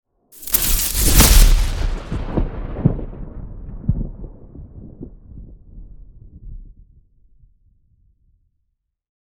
Electrifying Lightning Strike 4 Sound Effect Download | Gfx Sounds
Electrifying-lightning-strike-4.mp3